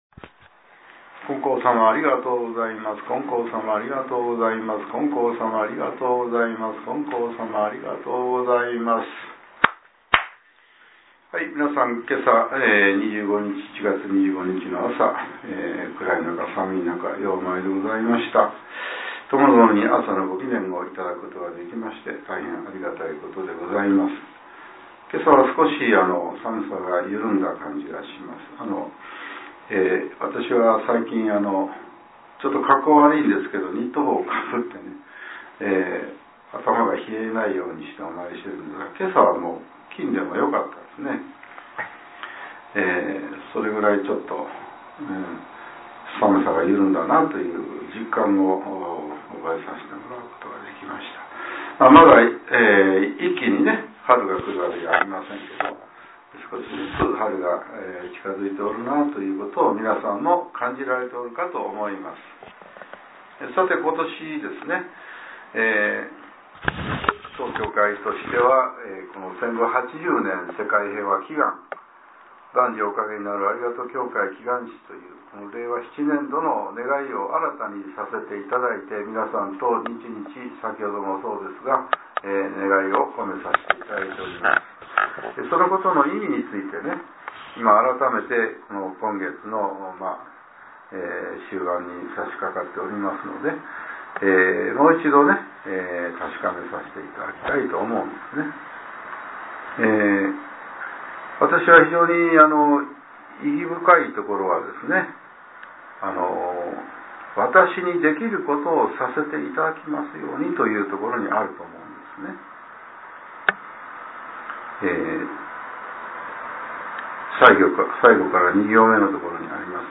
令和７年１月２５日（朝）のお話が、音声ブログとして更新されています。